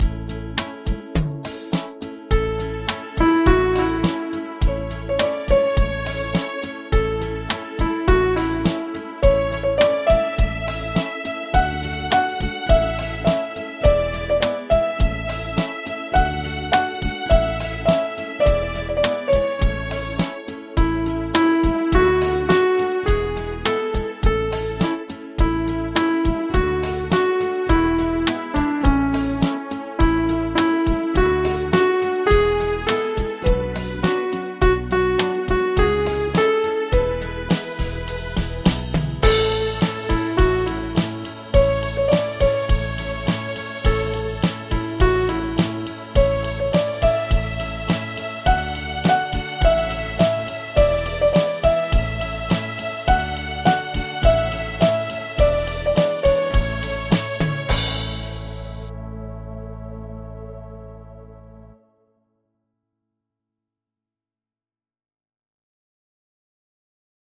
Audio Midi Bè Tenor: download